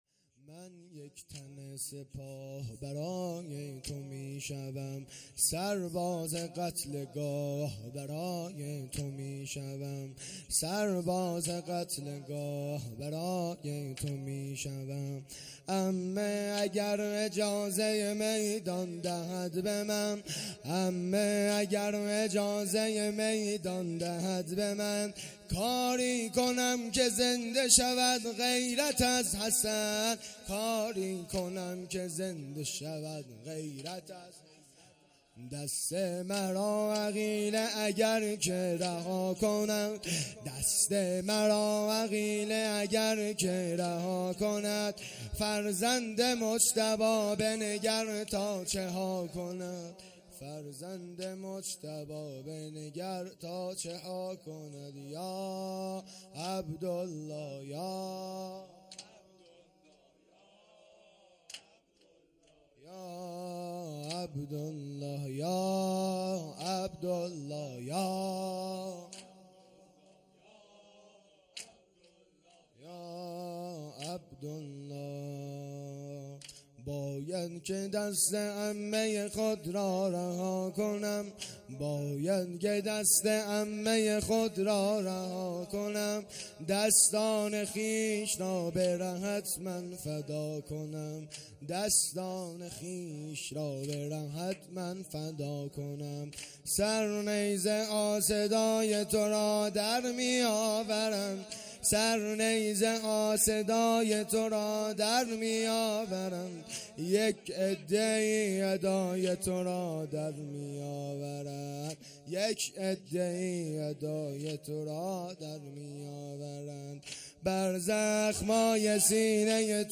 شب پنجم محرم الحرام ۱۴۴۳